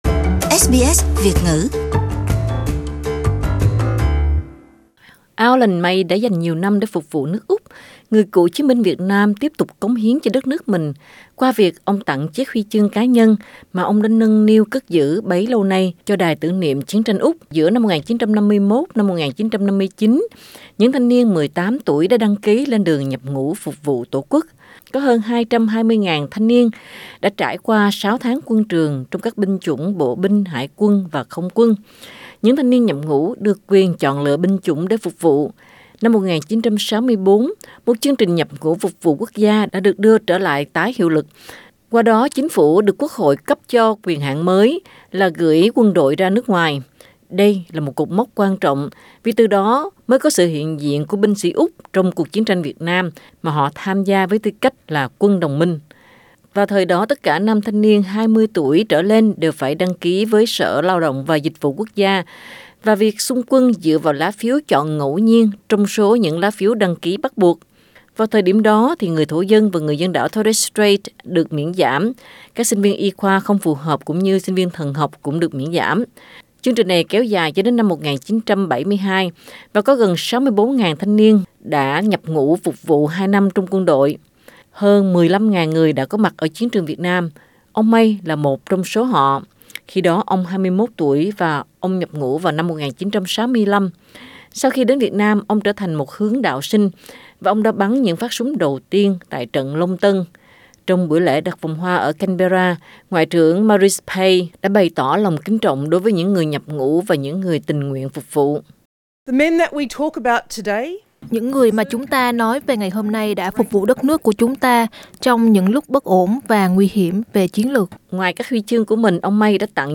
A ceremony at the Australian War Memorial commemorating the service of national servicemen Source: SBS